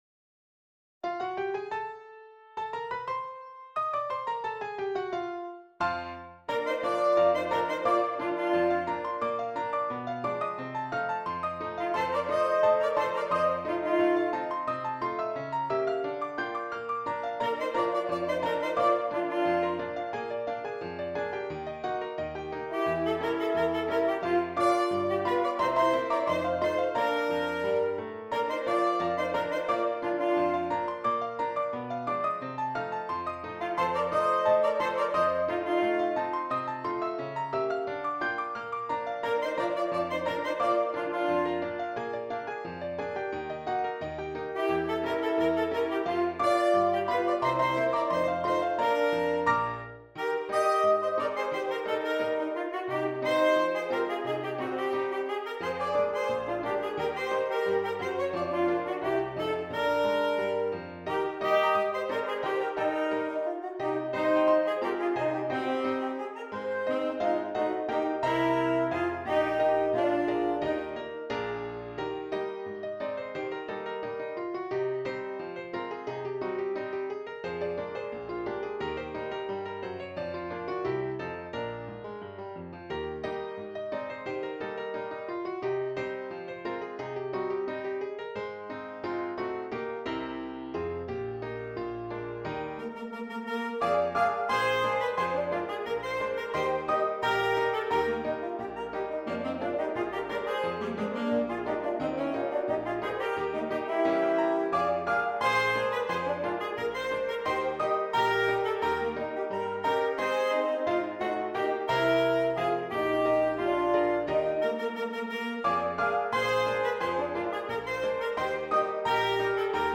2 Alto Saxophones and Keyboard
2 alto saxophones and piano